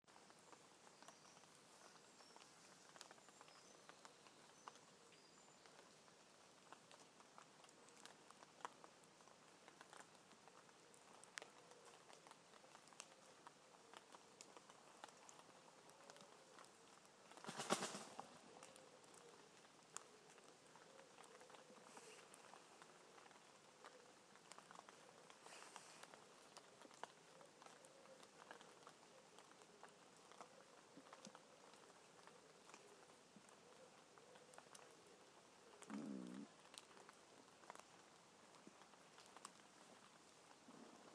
A better recording of the mist falling from the trees at Cley, Norfolk with a guest appearance from my stomach as it rumbled in need of breakfast.